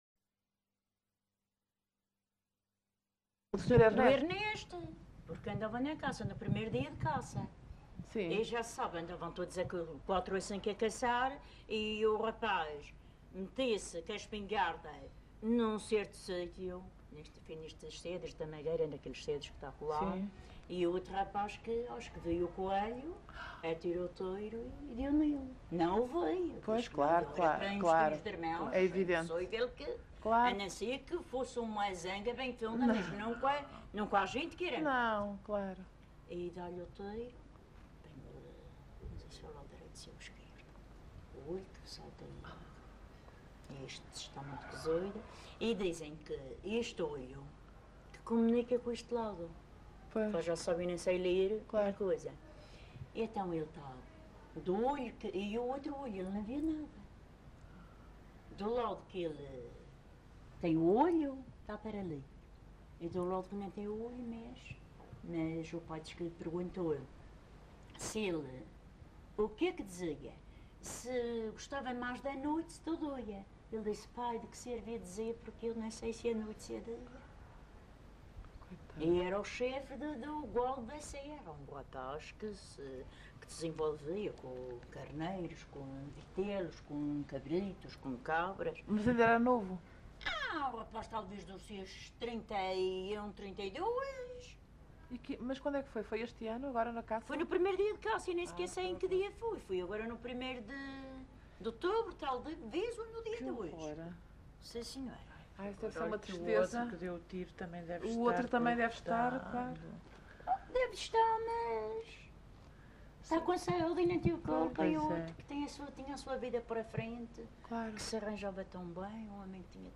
LocalidadeCamacha (Porto Santo, Funchal)